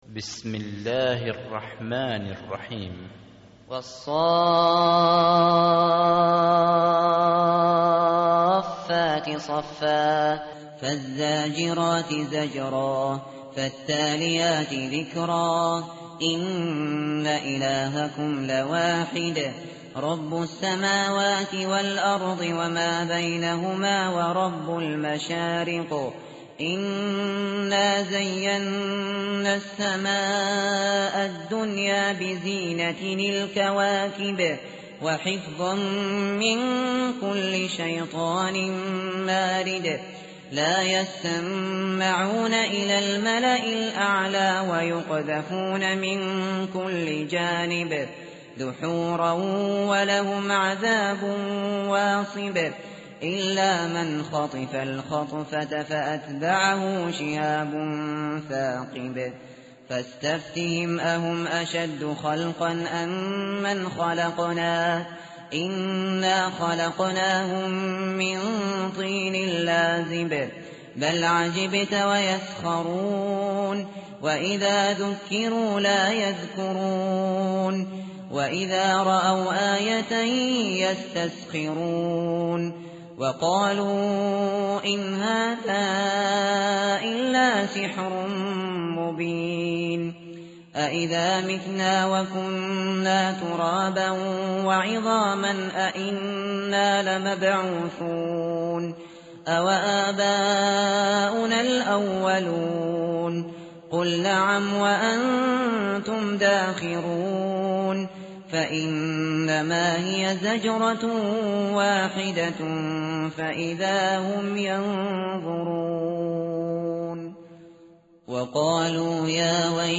tartil_shateri_page_446.mp3